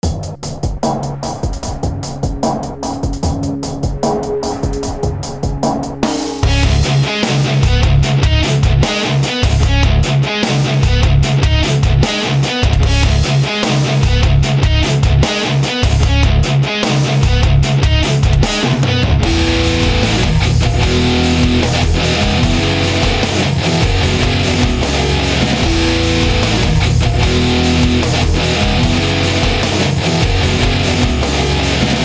Электрогитара "Ibanez RG 350 EX Black" .
Несколько фрагментов с этой гитарой: